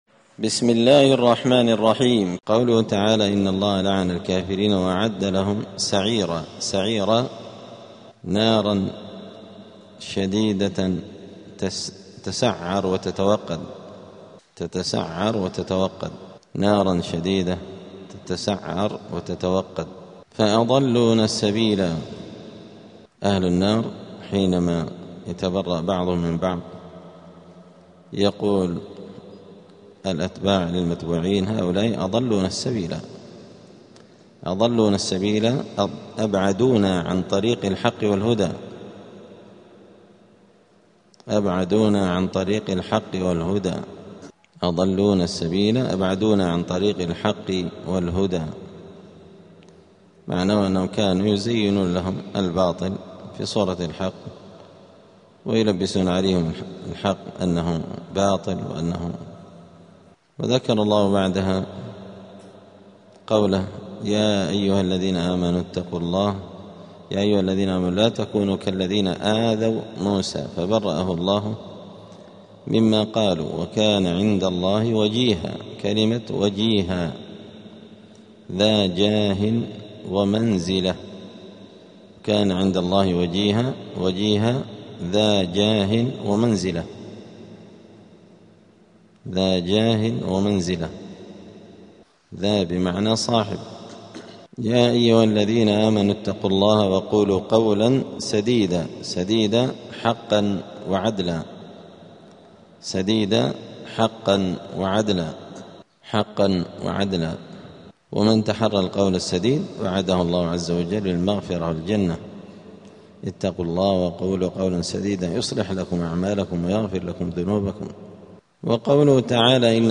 الأربعاء 11 رجب 1447 هــــ | الدروس، دروس القران وعلومة، زبدة الأقوال في غريب كلام المتعال | شارك بتعليقك | 6 المشاهدات